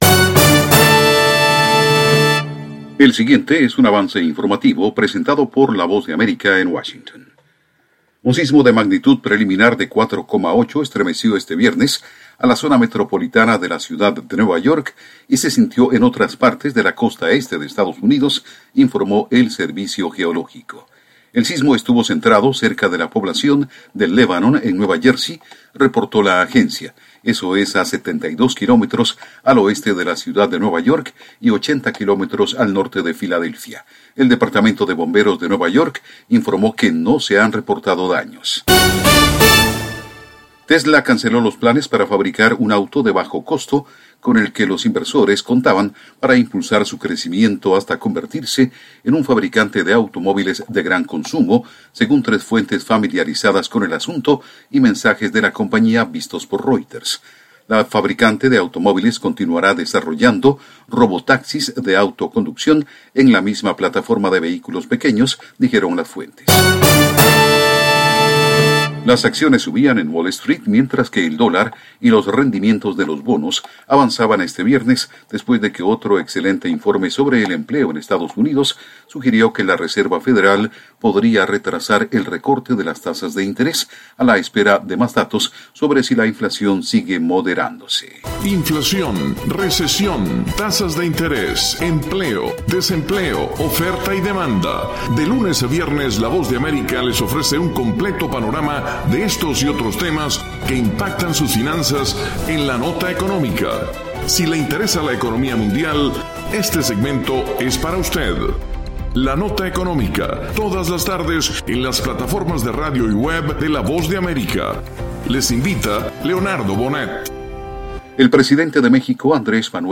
Avance Informativo 2:00 PM
El siguiente es un avance informativo presentado por la Voz de America en Washington